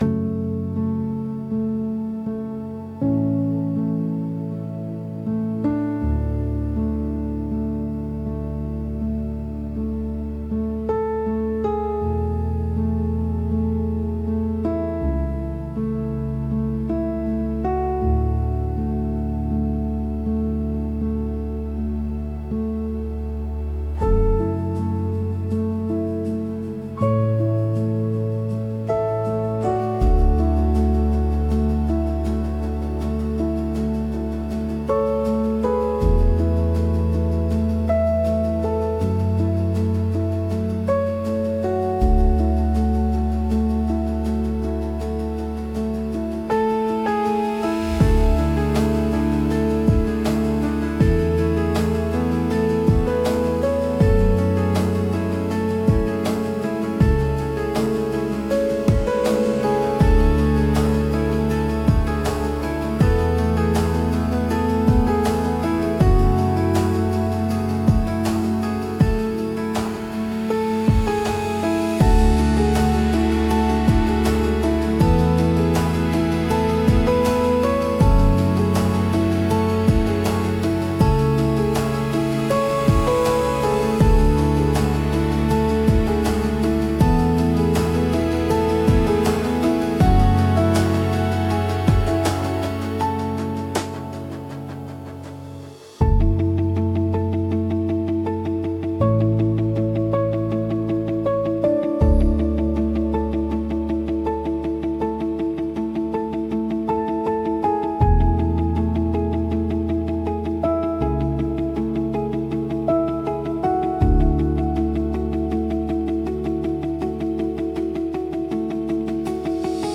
Instrumental: (Remastered)